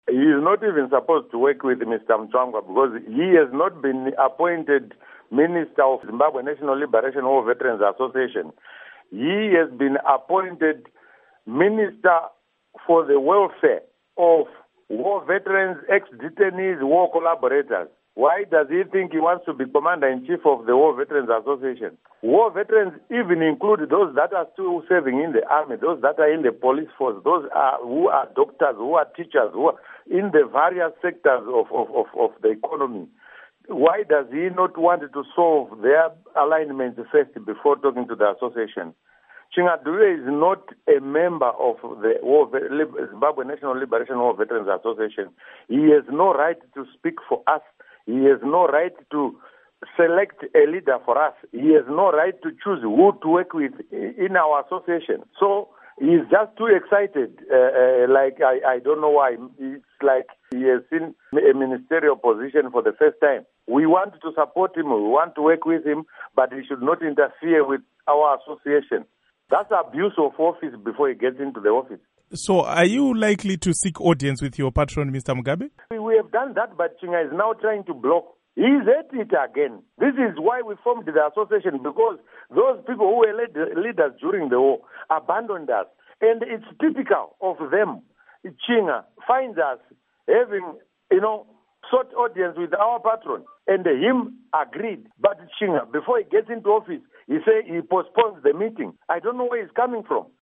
Interview With Victor Matemadanda